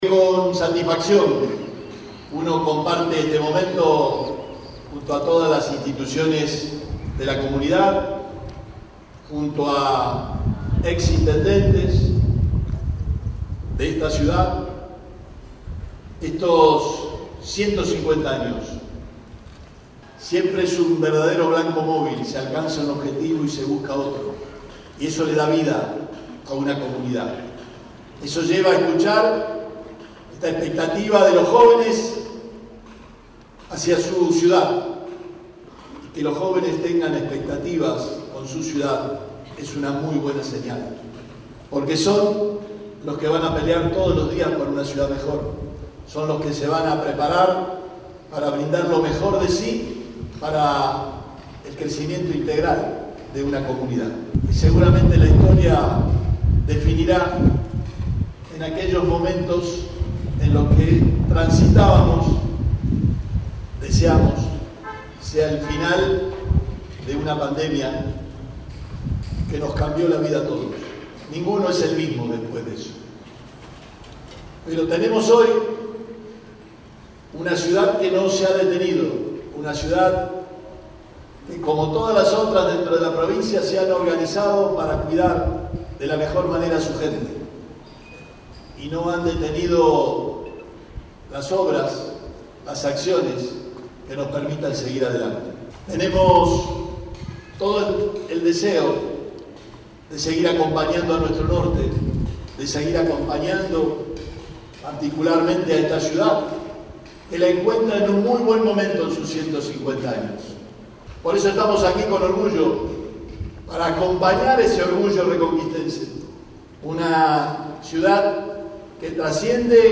El gobernador Omar Perotti participó de la celebración por los 150 años de la ciudad de Reconquista